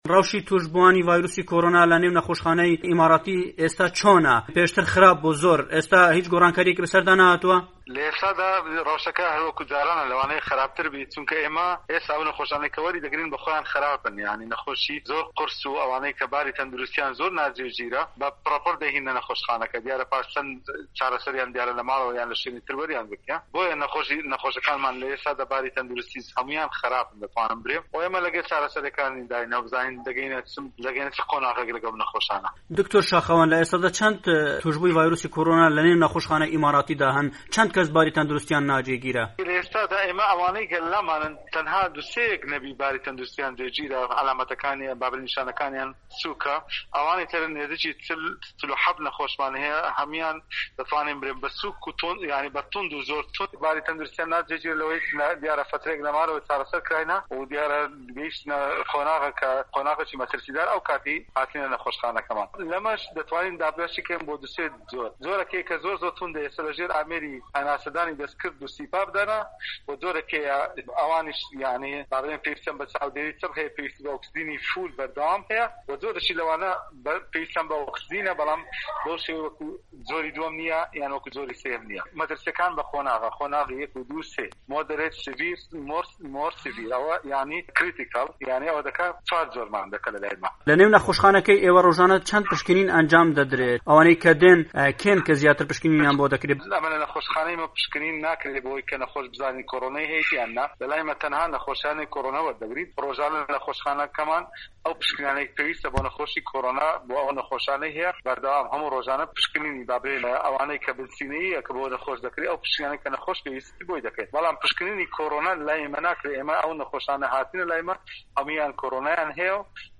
دەقی وتووێژەکە!